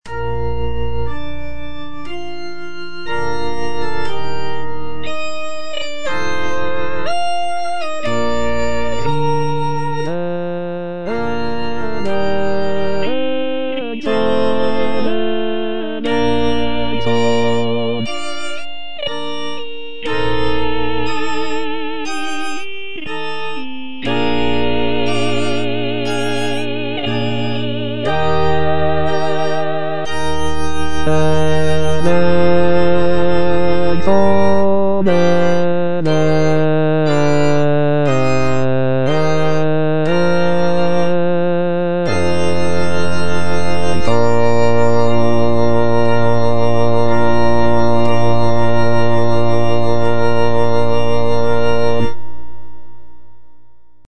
J. HAYDN - THERESIENMESSE HOB.XXII:12 Kyrie III (adagio) - Bass (Voice with metronome) Ads stop: auto-stop